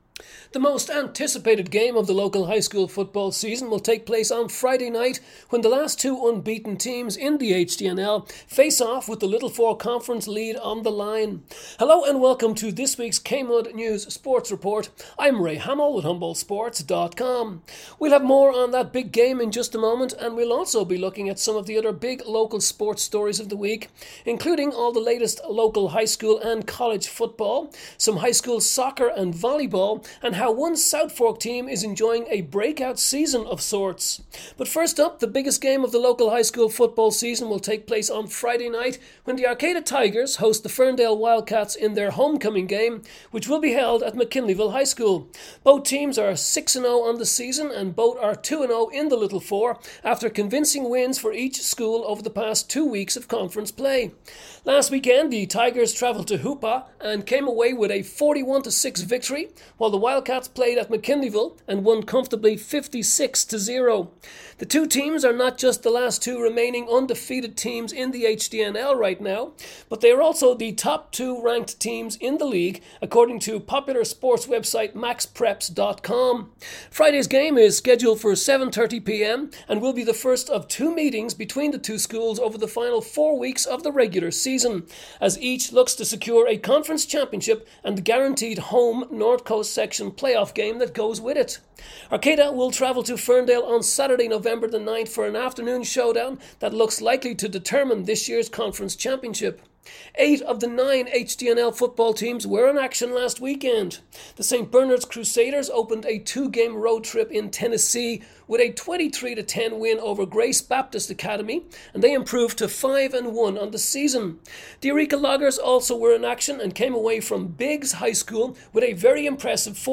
Oct 17 KMUD Sports Report